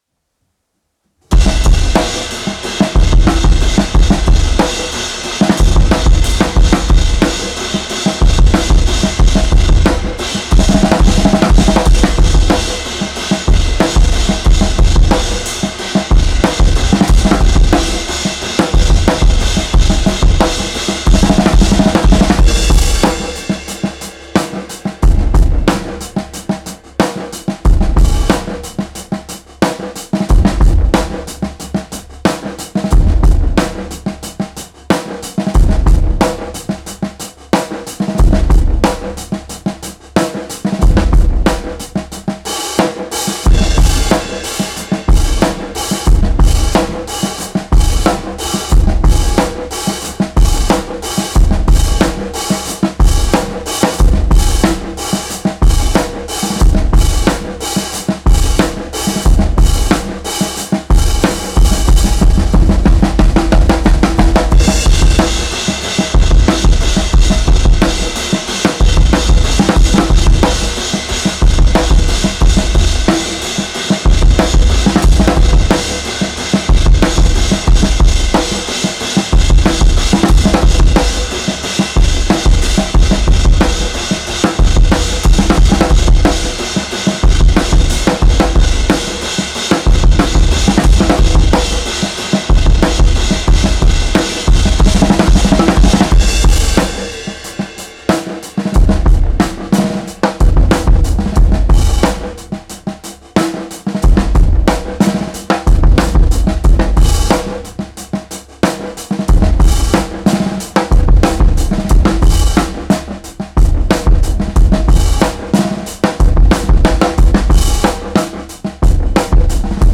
Hip-hop
Genre:Hip-hop, Funk
Tempo:182.250 BPM (4/4)
Kit:Marching kit 30"
Mics:12 channels